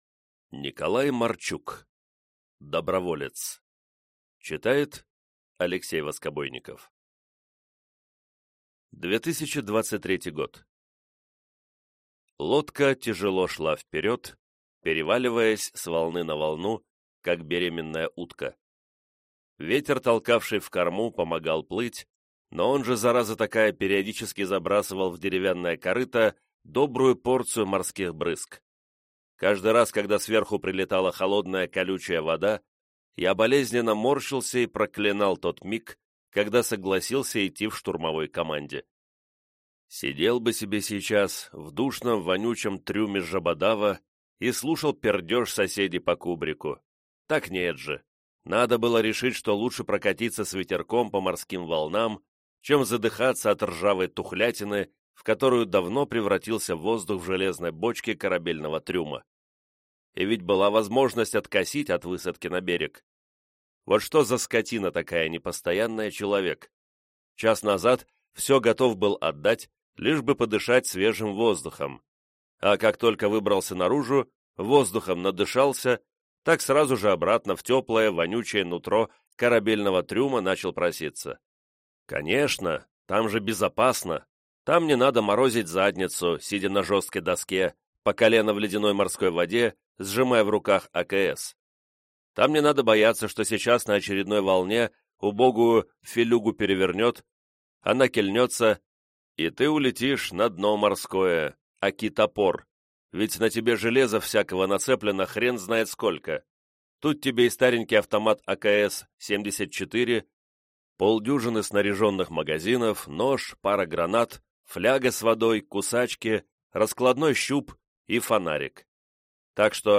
Аудиокнига Доброволец | Библиотека аудиокниг